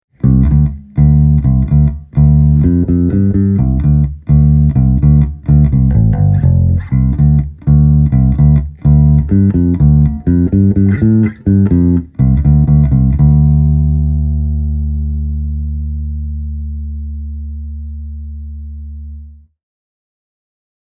Engaging Character noticeably pumps up the bass and adds muscle to the proceedings:
Character on – EQ off